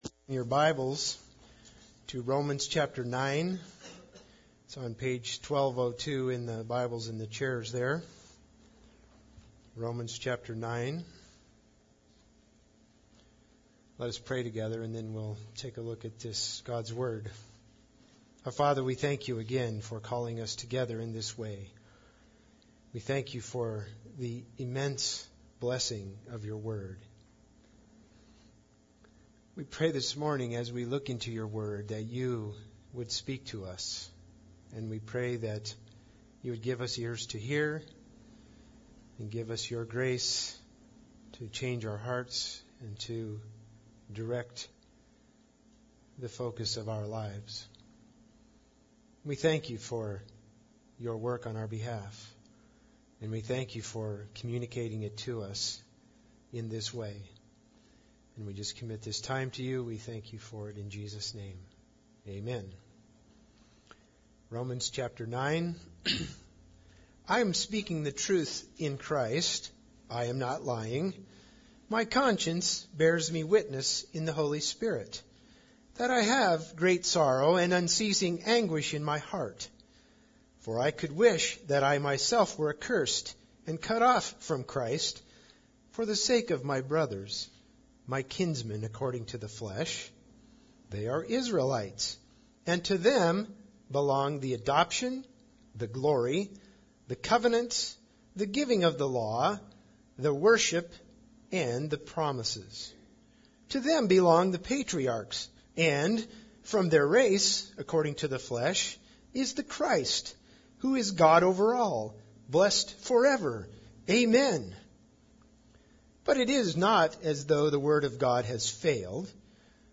Romans 9:1-26 Service Type: Sunday Service Bible Text